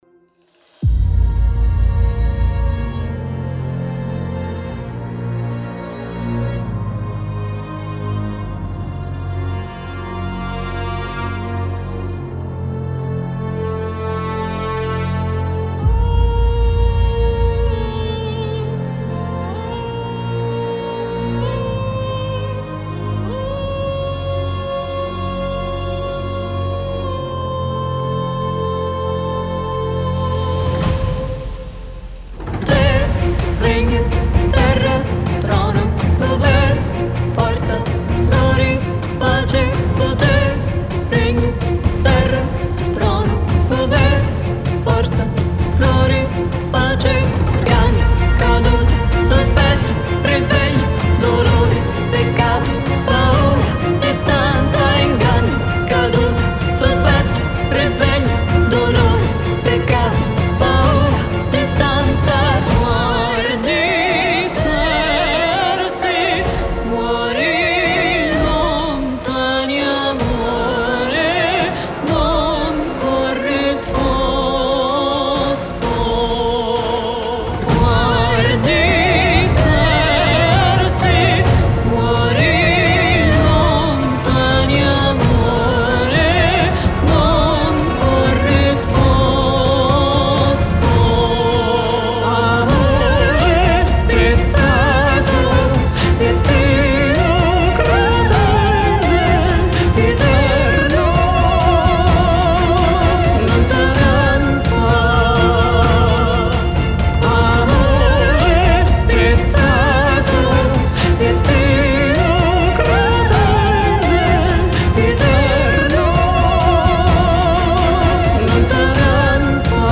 Genre:Cinematic
128 BPMという使いやすいテンポで録音されており、クリエイティブなプロジェクトに自然に溶け込みます。
各サンプルは彼女の声の深みと豊かさを捉えており、あなたの作品にドラマと情熱を響かせることを保証します。
40 Dry Vocal Loops
40 Wet Vocal Loops
25 Dry Adlib Loops
20 Chopped Vocal Loops